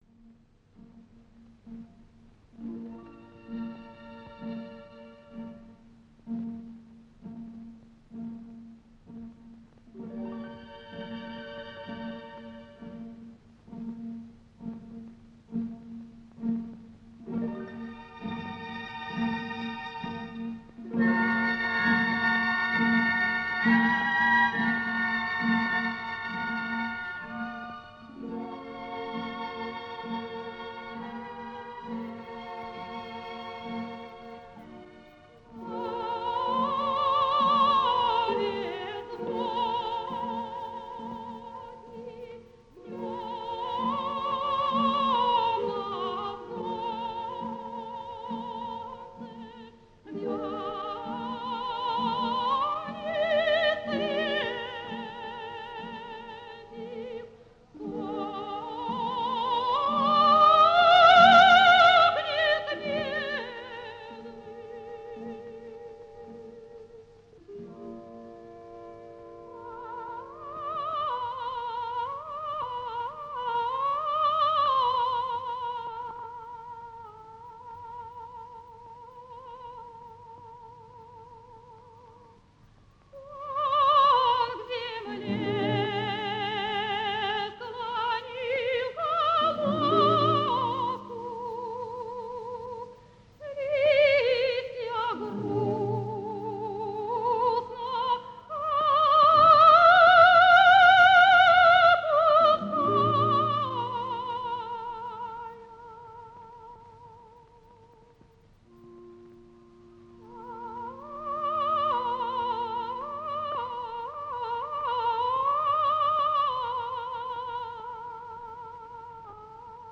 сопрано
Опера «Князь Игорь». Песня Половецкой девушки. Хор и оркестр Большого театра. Дирижёр А. Ш. Мелик-Пашаев.